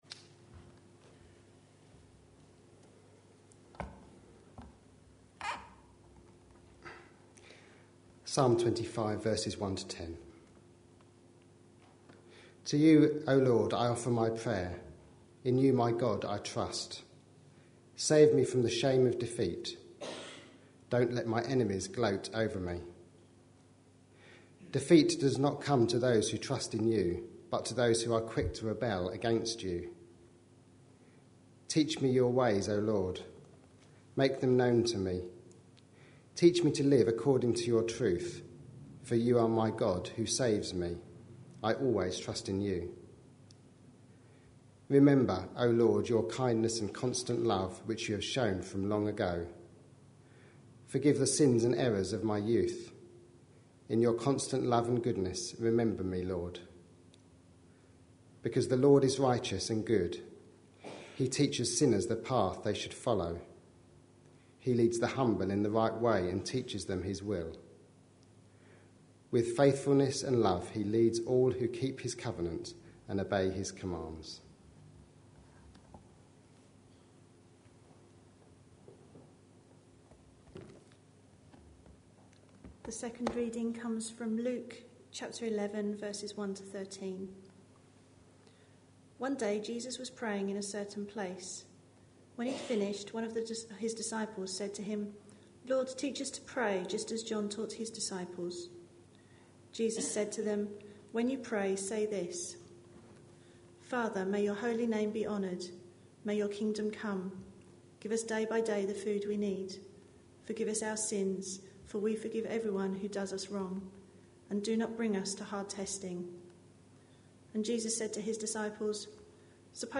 A sermon preached on 9th February, 2014, as part of our Stories with Intent series.